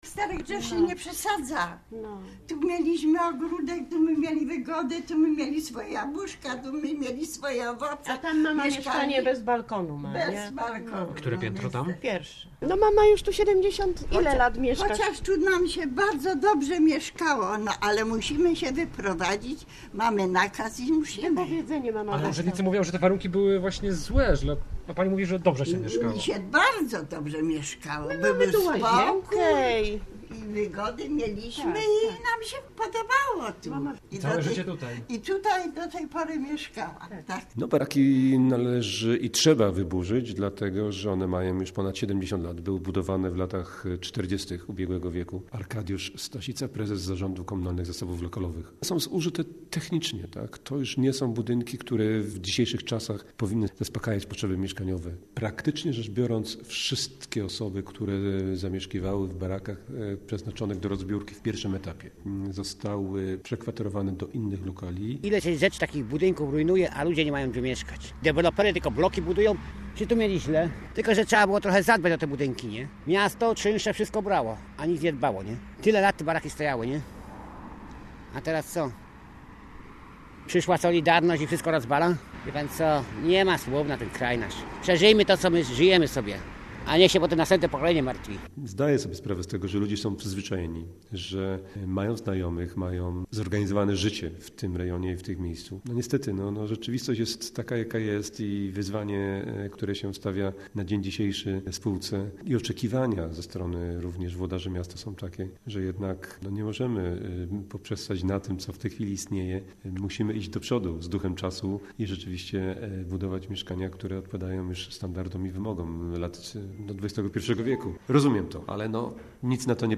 Nasz reporter pojechał dziś na ul. Opolską i wysłuchał historii mieszkańców, którzy po latach muszą opuścić dotychczasowe domy.